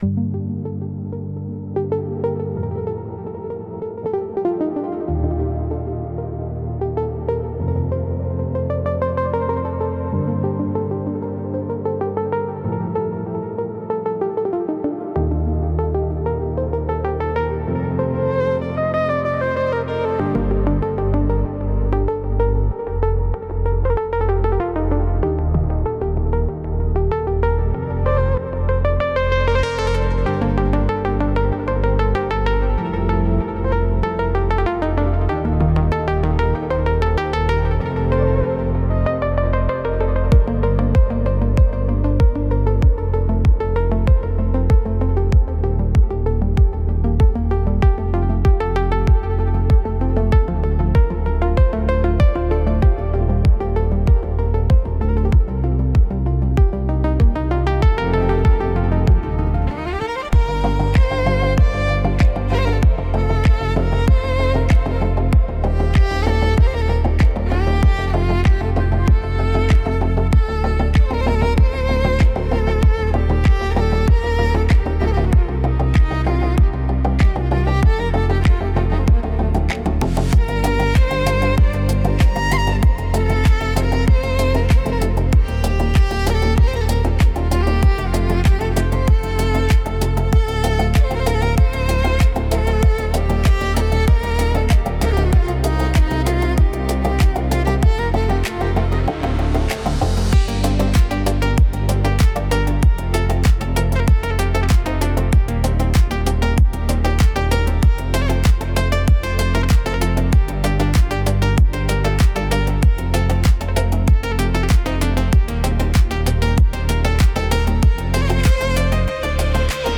Organic House Remix